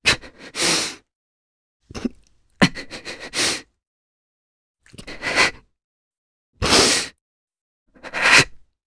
Pavel-Vox_Sad_jp.wav